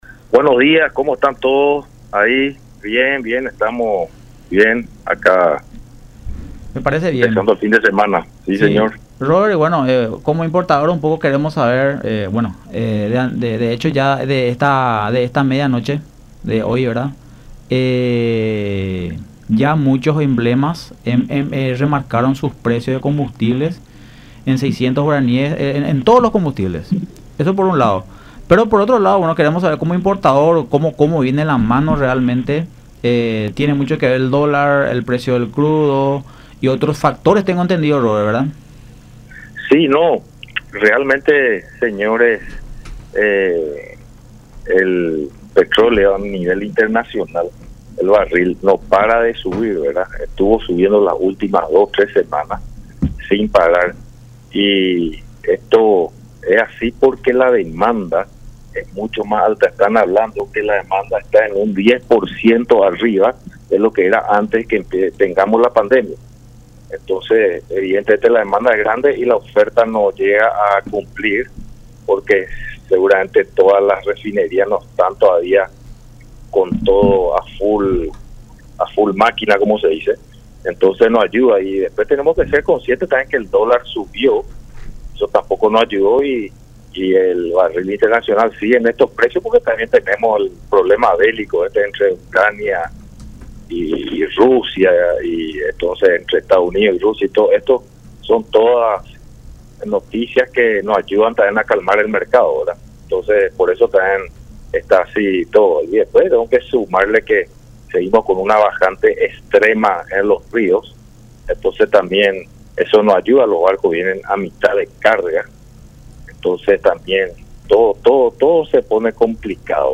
en conversación con Hablemos de Economía por La Unión